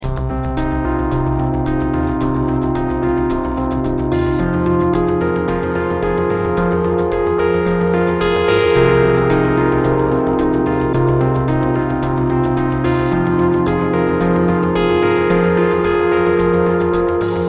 Electro-music-loop-110-bpm.mp3